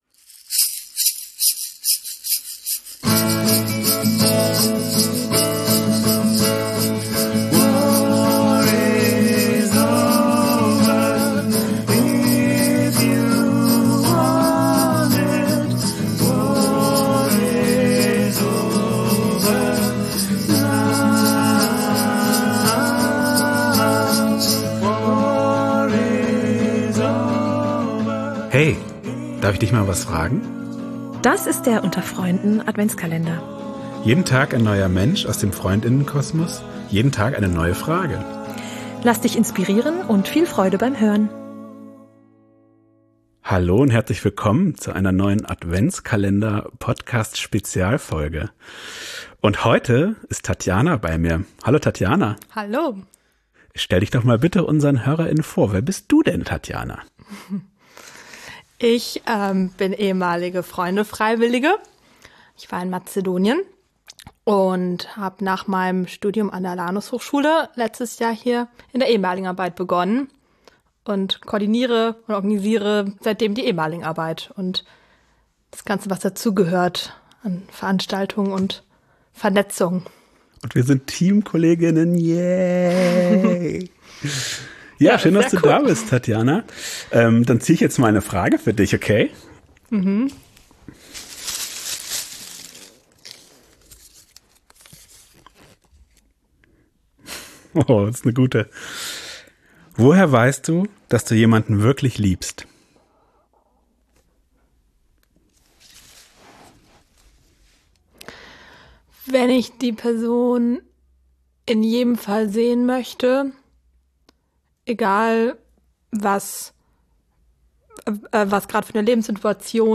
In unserem Adventskalender zum Hören wird jeden Tag eine andere Person aus dem Freundinnen-Kosmos zu einer persönlich-philosophischen Frage kurz interviewt und darf spontan dazu antworten. Jeden Tag eine Inspiration und ein Denkanstoß.